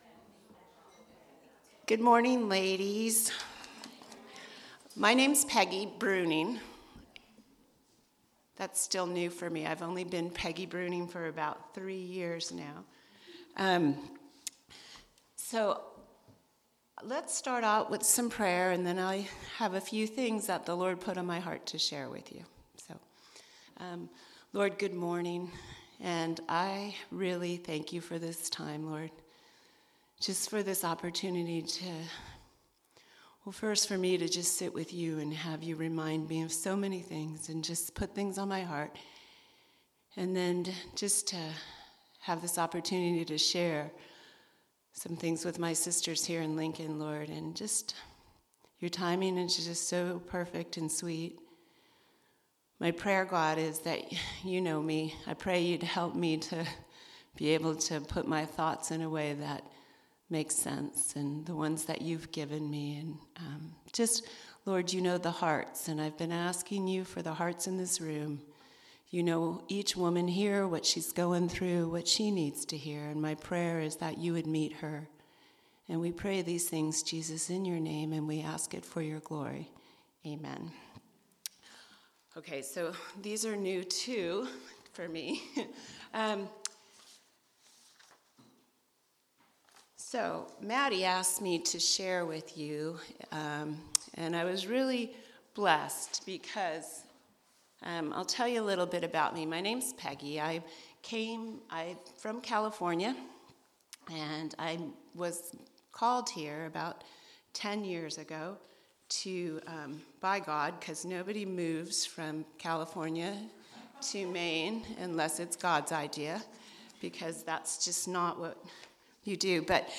Ladies Breakfast